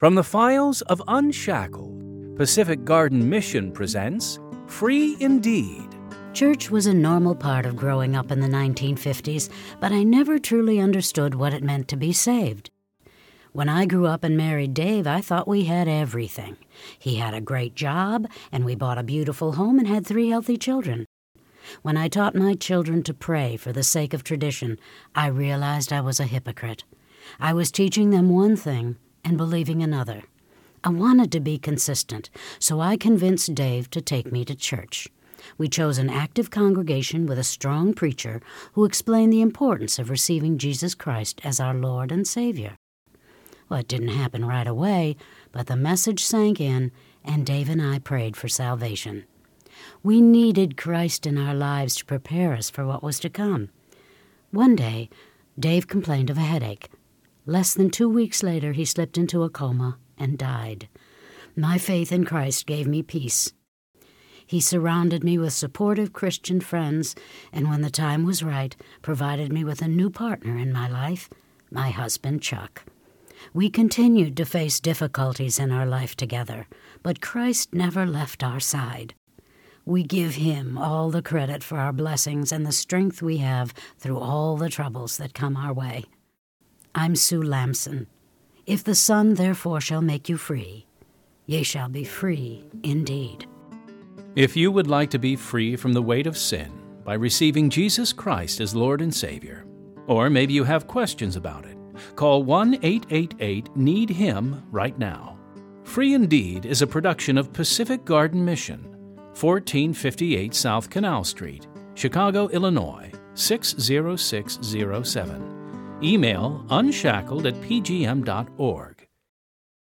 Two Minute Testimonies of True Life Stories